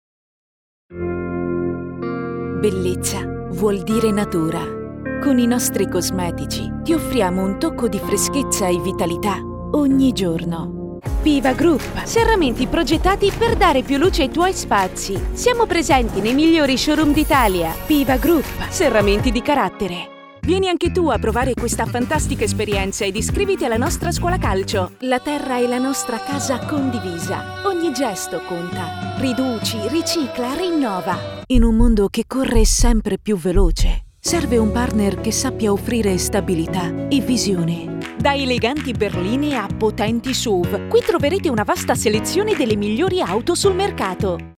Professional voice actress with a fresh, dynamic and extremely versatile voice.
Sprechprobe: Werbung (Muttersprache):